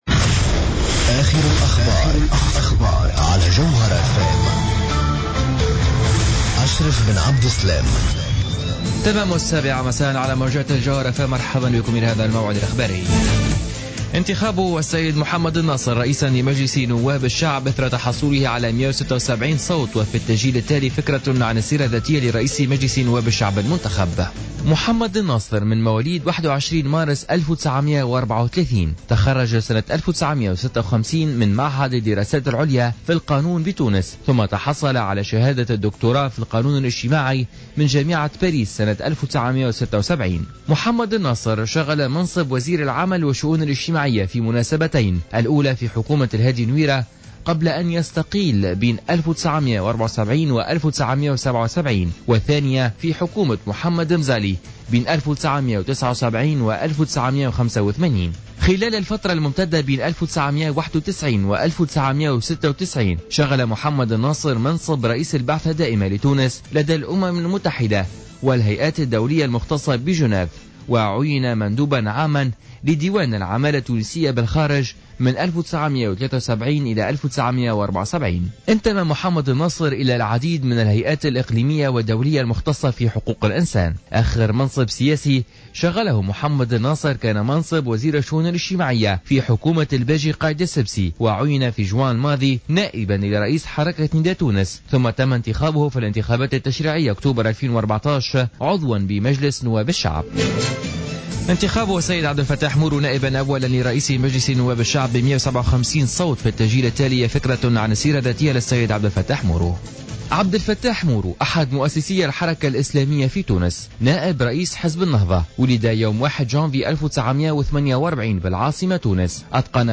نشرة أخبار السابعة مساء ليوم الخميس 04-12-14